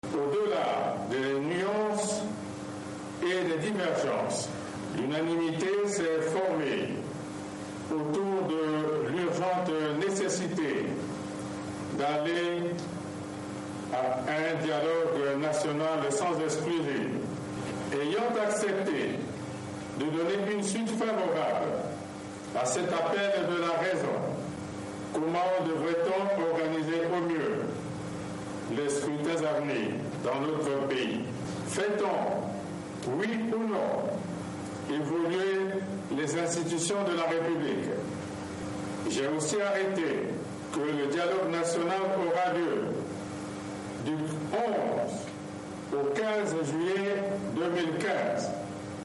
Allocution de Denis Sassou Nguesso